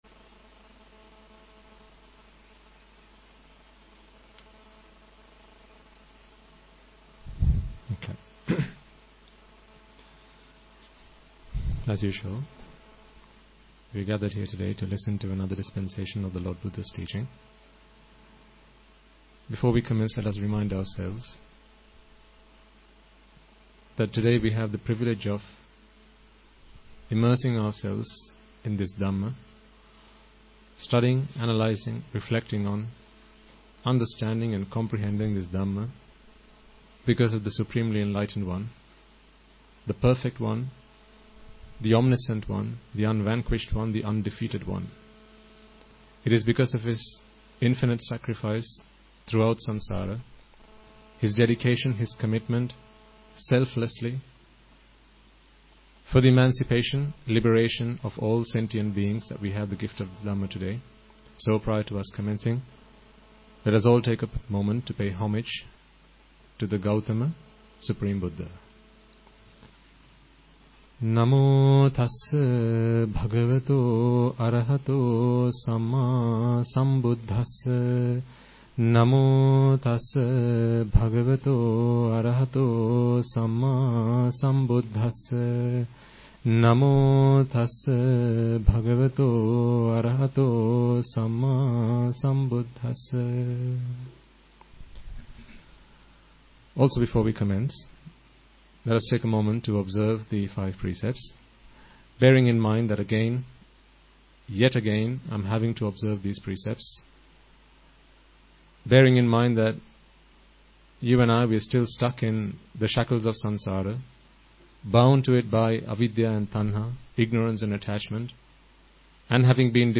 English Dhamma Sermon on 2018-12-16